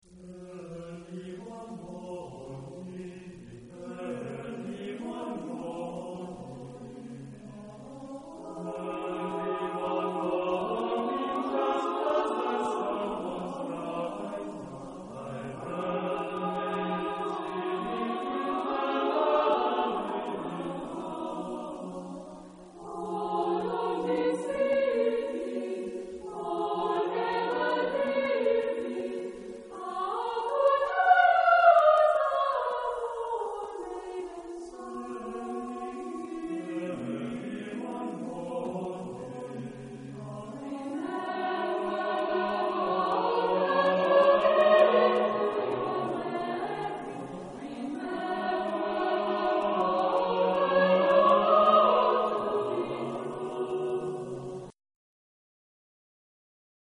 Epoque: 20th century
Type of Choir: SATB  (4 mixed voices )